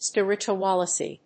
音節spir・it・u・al・ize 発音記号・読み方
/spírɪtʃuəlὰɪz(米国英語)/